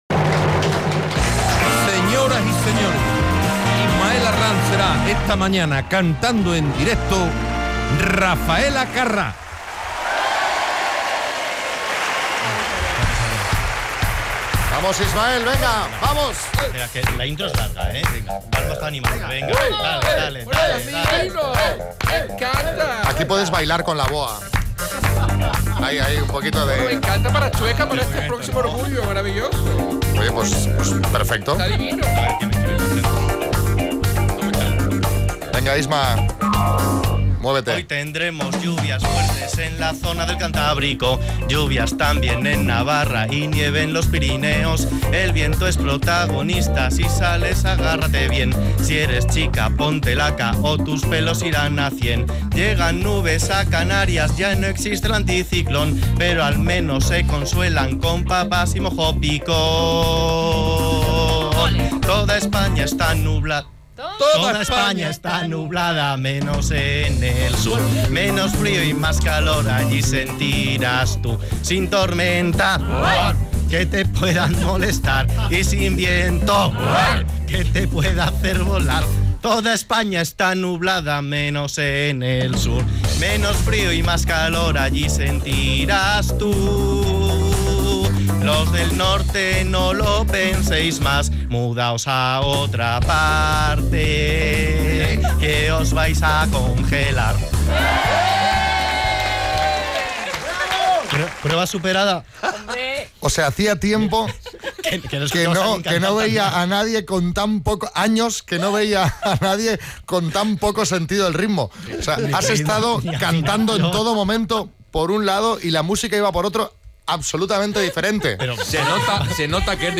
hoy se ha animado a cantarnos el tiempo divertido